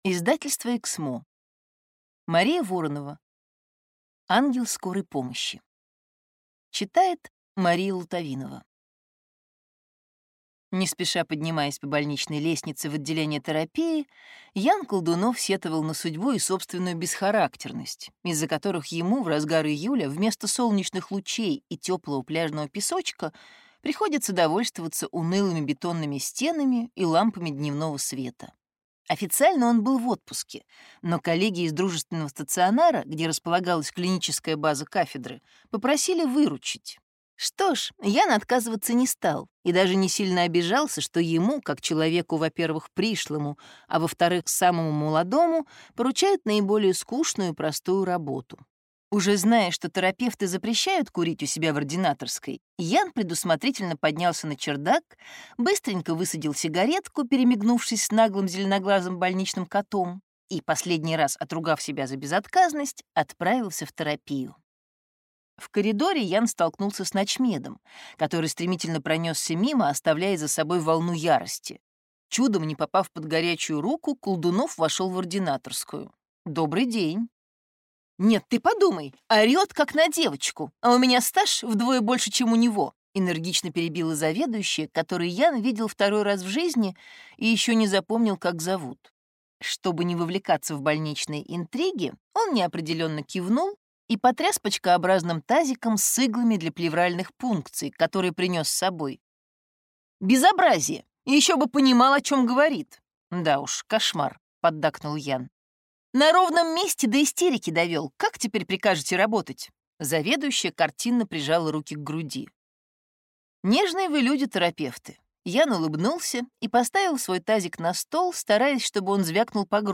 Аудиокнига Ангел скорой помощи | Библиотека аудиокниг